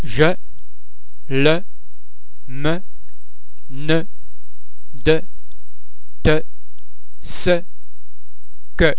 ·[ e ] in the monosyllabic words